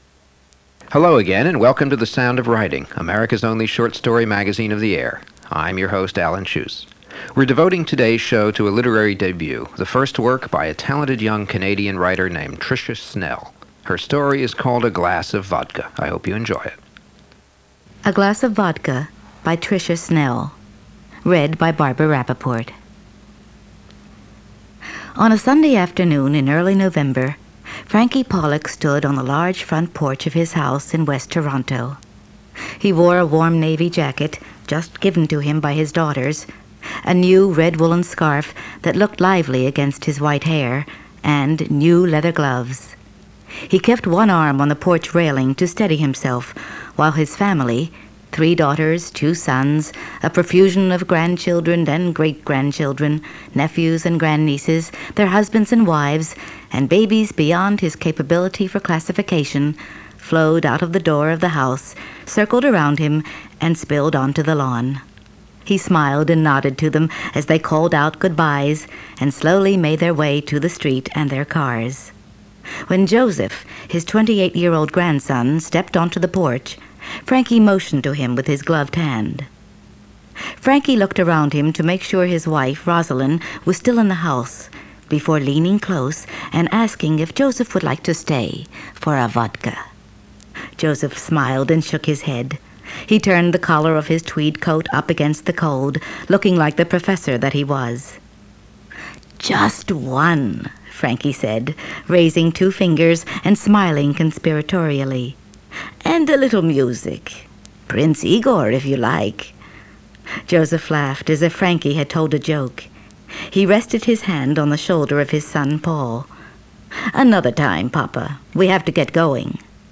A-Glass-of-Vodka_-for-NPR-Sound-of-Writing-show.m4a